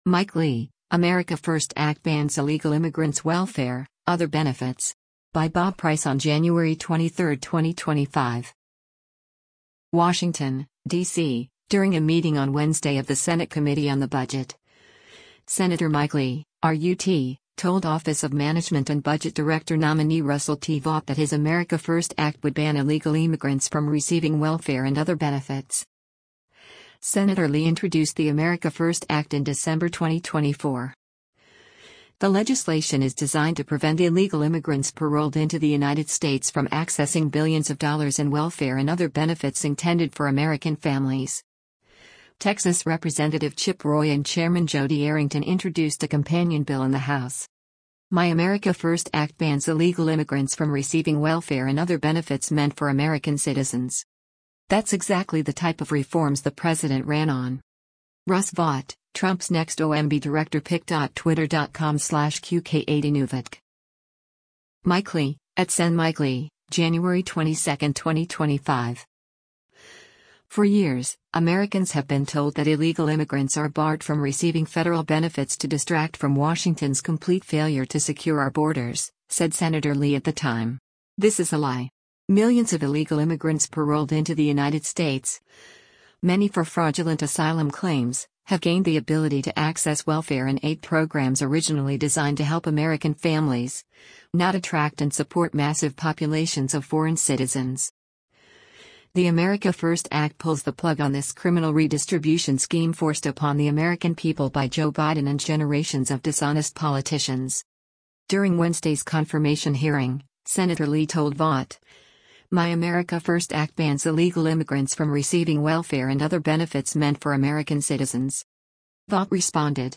WASHINGTON, DC — During a meeting on Wednesday of the Senate Committee on the Budget, Senator Mike Lee (R-UT) told Office of Management and Budget Director Nominee Russell T. Vought that his America First Act would ban illegal immigrants from receiving welfare and other benefits.
During Wednesday’s confirmation hearing, Senator Lee told Vought, “My America First Act bans illegal immigrants from receiving welfare and other benefits meant for American citizens.”